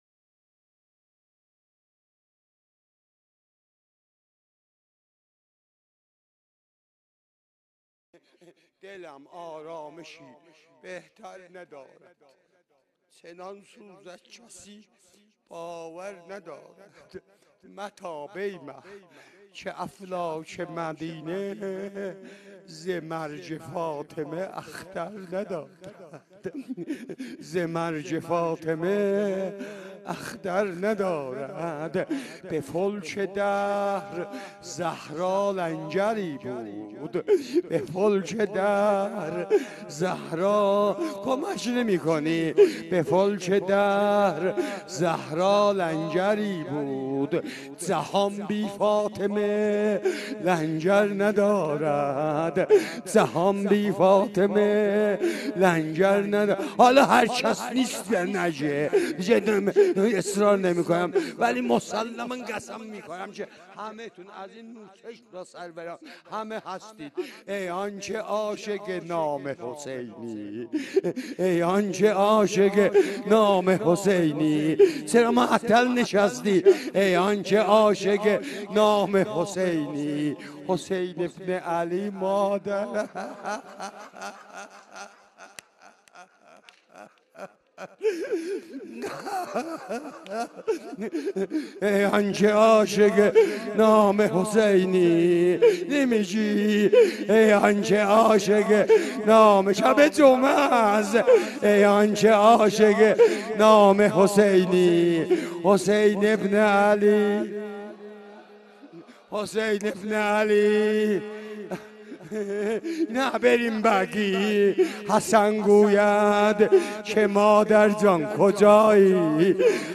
روضه | دلم آرامشی بهتر ندارد، چنان سوزد کسی باور ندارد
روز چهارم فاطمیه اول، اسفند ماه سال 1393